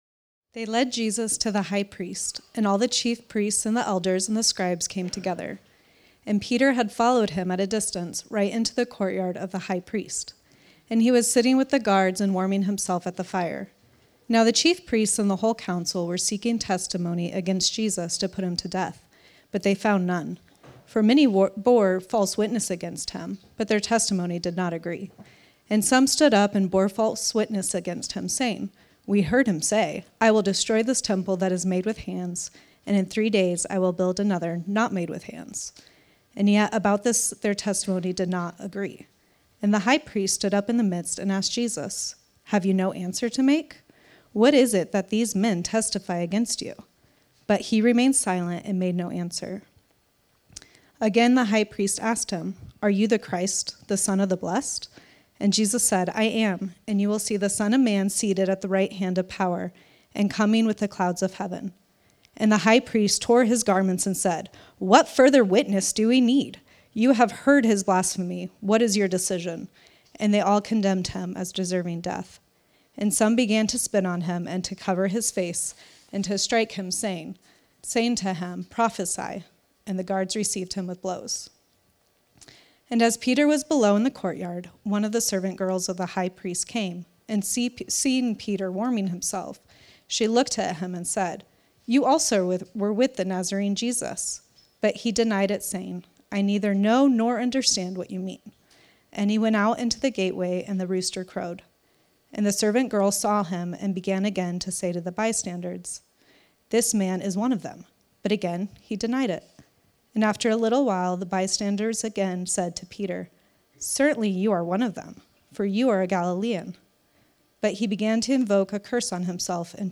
Mission Church is a small and relationally minded Christian community in the center of Tucson Arizona. Each teaching is based out of a Scripture passage and most are from within a study of an entire biblical book.